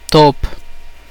Ääntäminen
CA : IPA: [toːp] Tuntematon aksentti: IPA: /top/